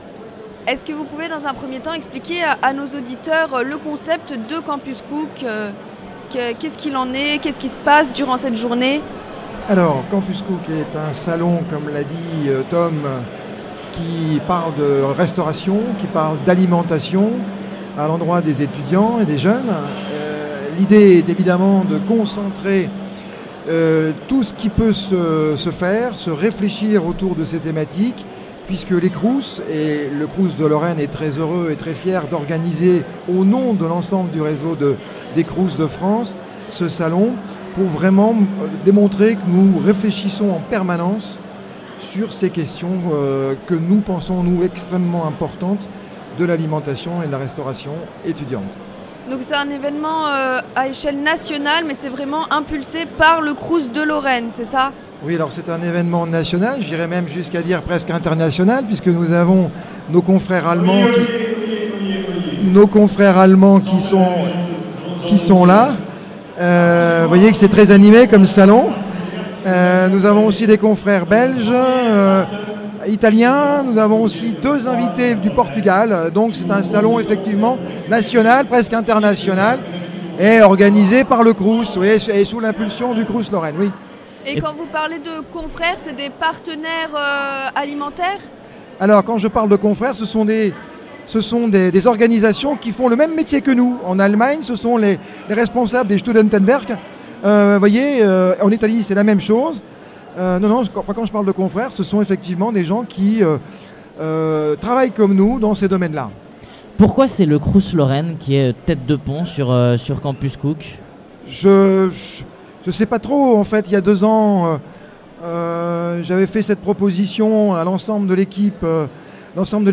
La 2e édition de Campus Cook bat son plein au Palais des Congrès de Nancy. Rencontre avec les professionnels de la restauration universitaire.